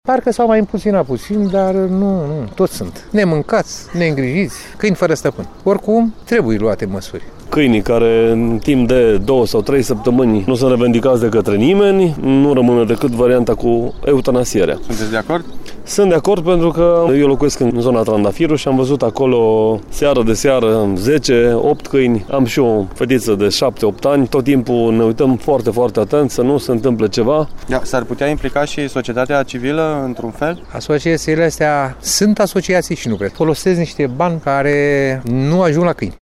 Problema câinilor vagabonzi ridică mari semne de întrebare şi în rândul cetăţenilor, iar părerile sunt împărţite:
vox-caini.mp3